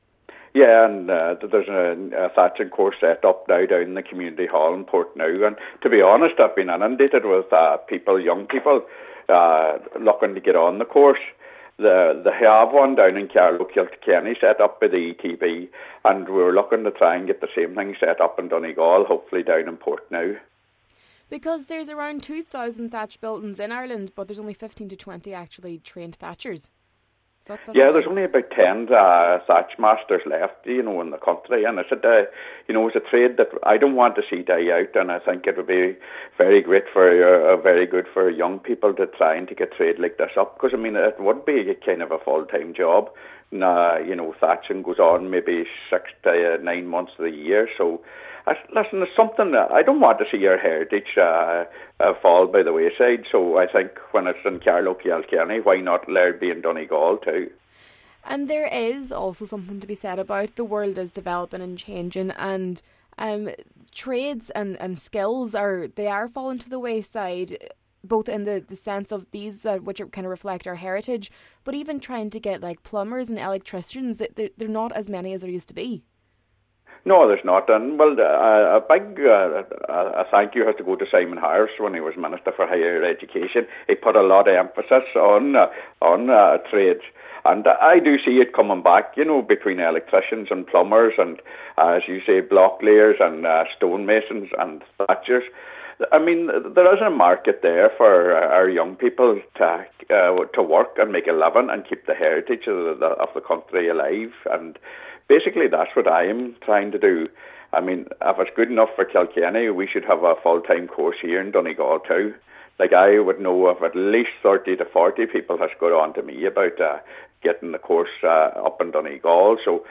Senator Boyle says he wants to see specialised traditional skills such as thatching, stonemasonry, and blacksmithery taught through the Donegal ETB: